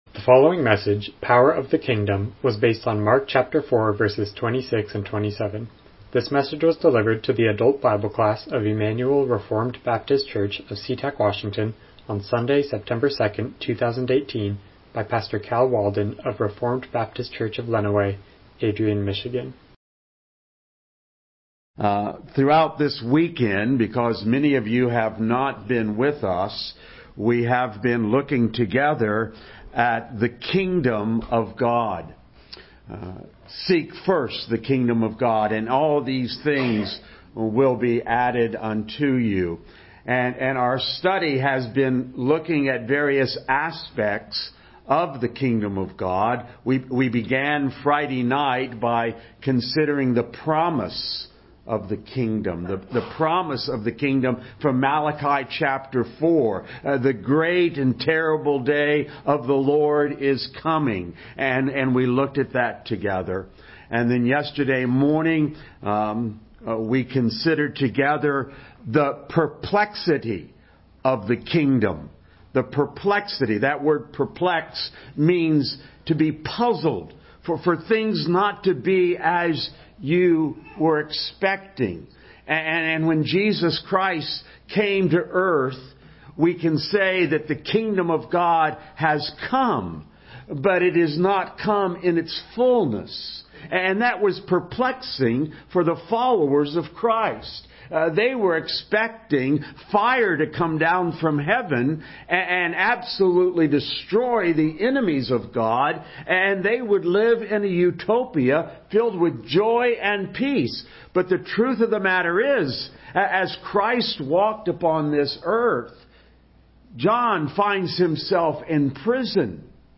Miscellaneous Passage: Mark 4:26-27 Service Type: Sunday School « Holiness is for Students